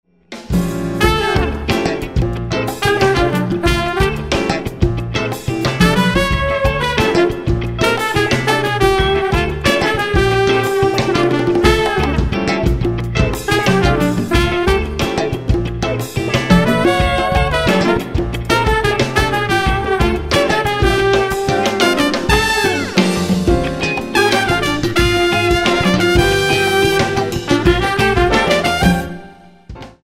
un brano atonale con ritmica afro e parecchi stacchi funky.